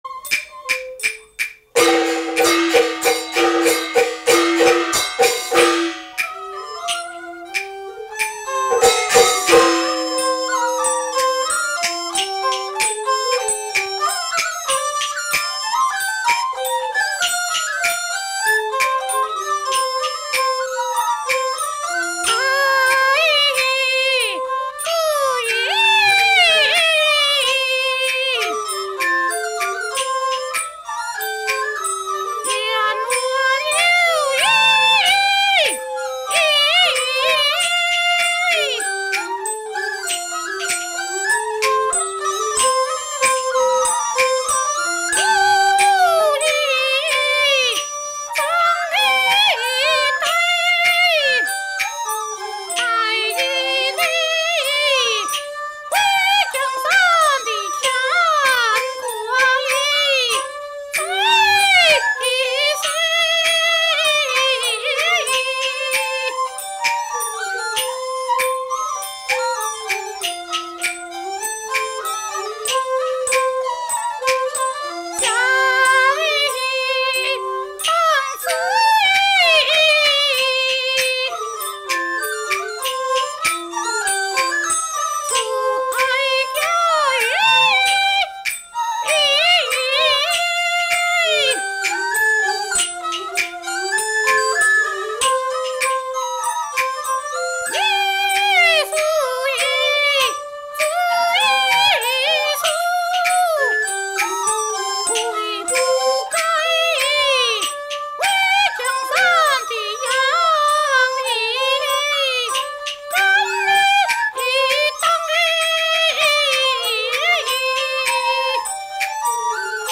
戲曲 - 三進宮選段1（二黃） | 新北市客家文化典藏資料庫